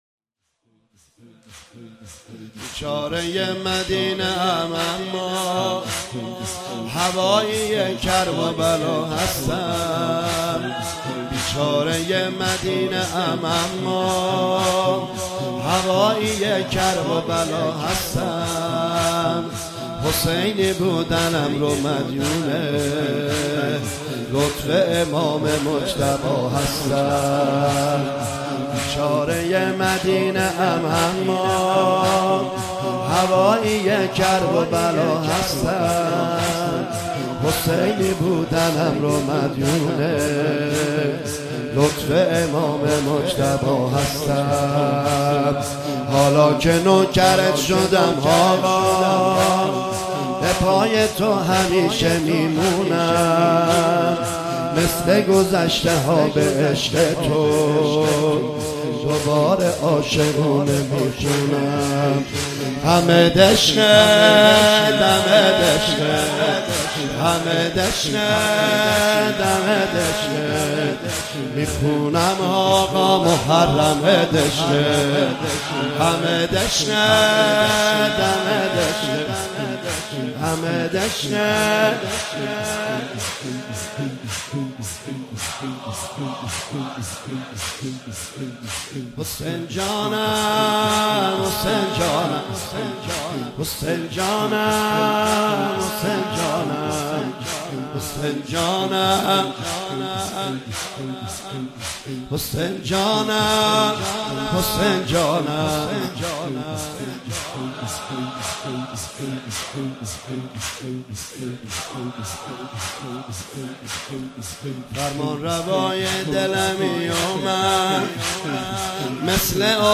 مداحی محرم
نوحه محرم